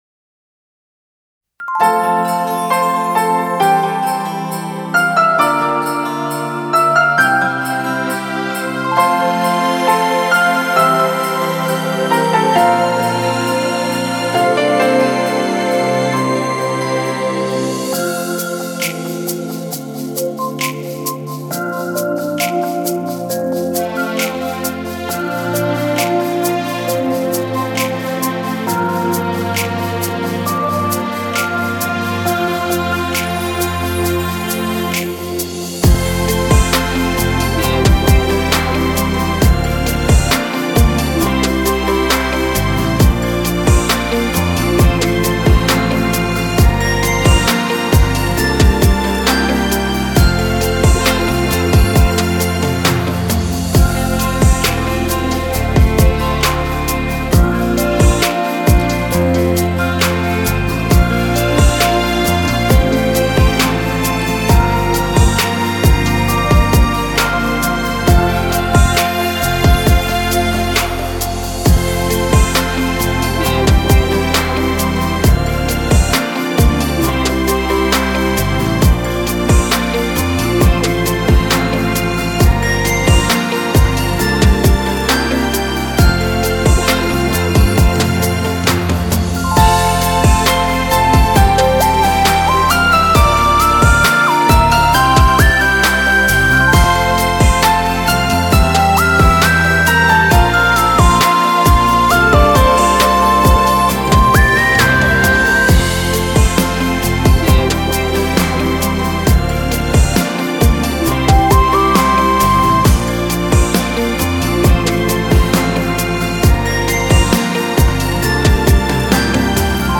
• Категория: Детские песни
караоке
минусовка